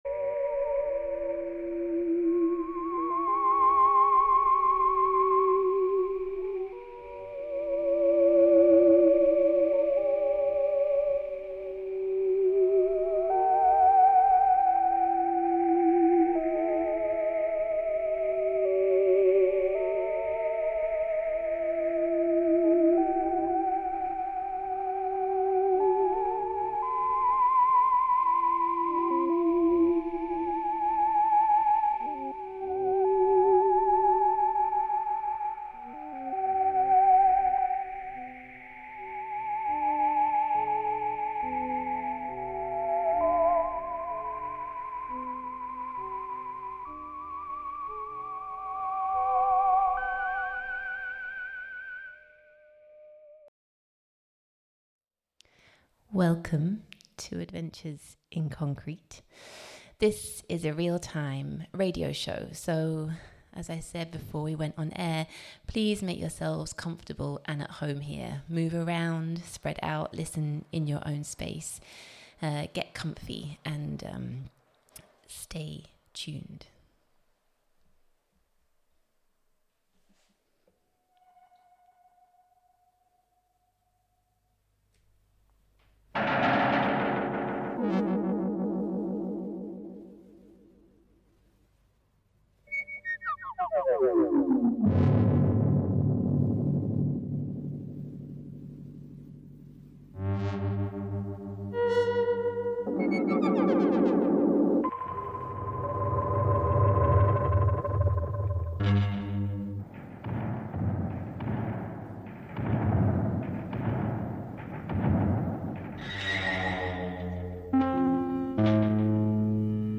Recorded live, on site at LjurhallaFabriken, for the Stewardship and Structures: Enacting, Regenerating and Maintaining seminar 24 Sept 2025.